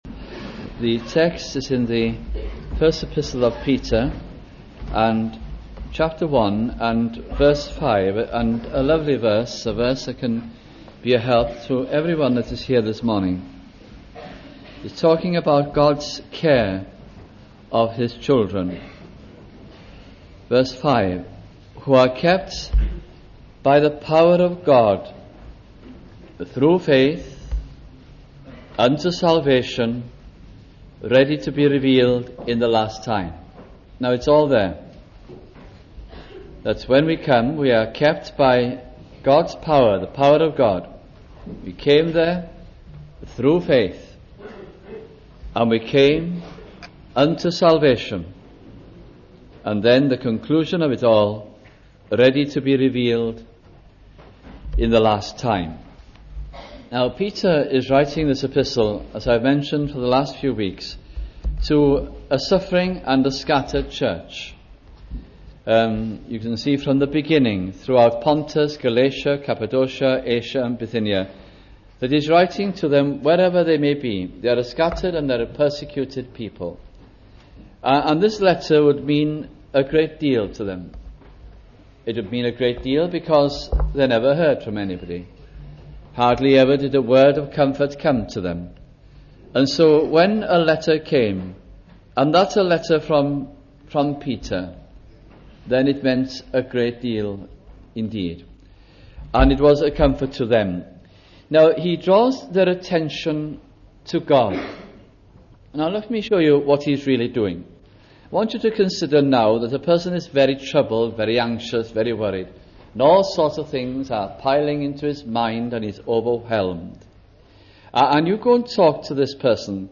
» First Epistle of Peter Series 1982 - 1983 » sunday morning messages from this gracious epistle